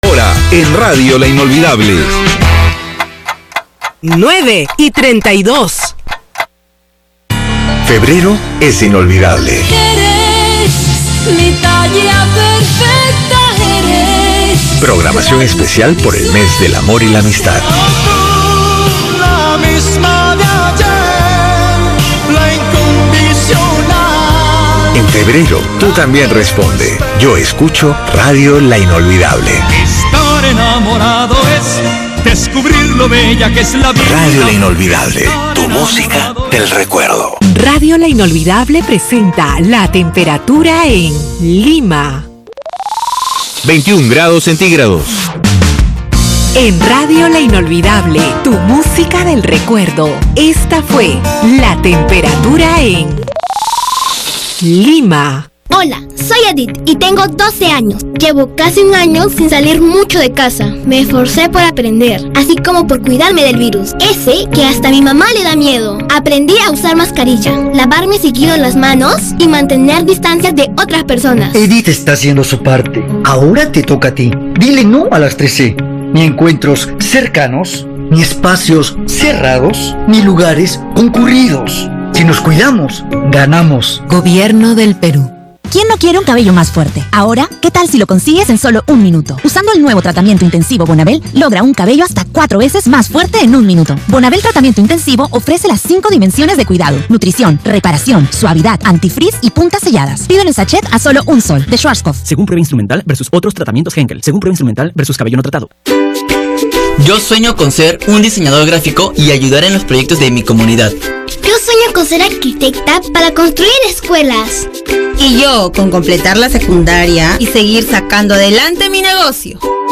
Aquí les traigo dos tandas comerciales de La Inolvidable 93.7 grabadas por mi computadora mediante un cable auxiliar hacia mi TV Sony